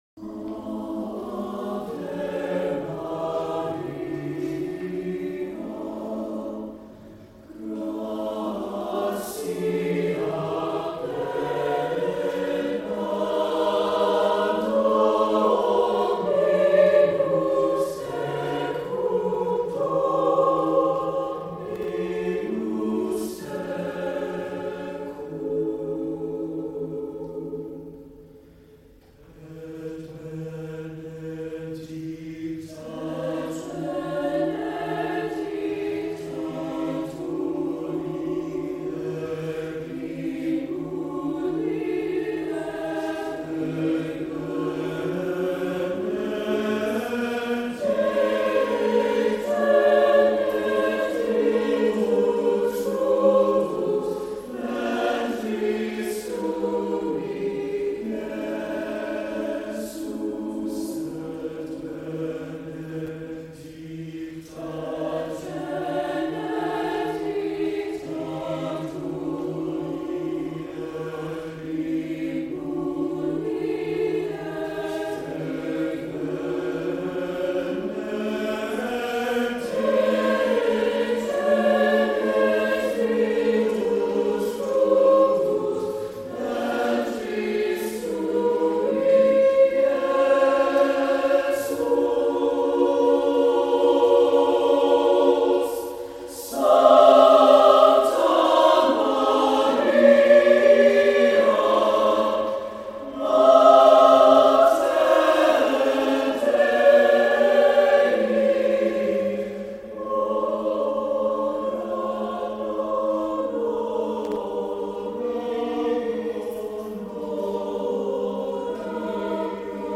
Accompaniment:      With Piano
Music Category:      Choral